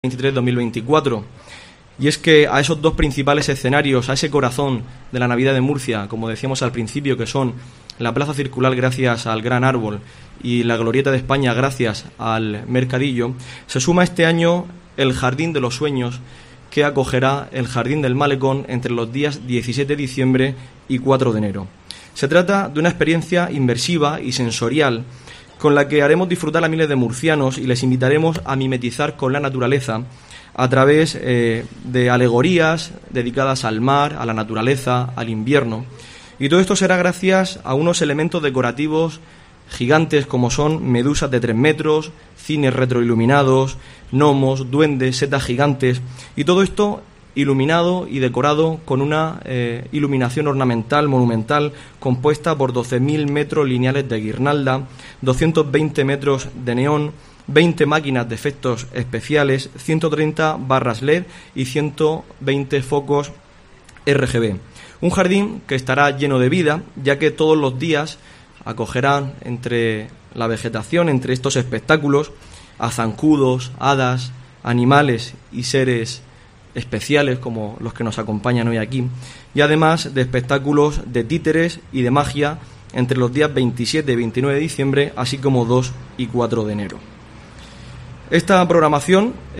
Diego Avilés, concejal de Cultura e Identidad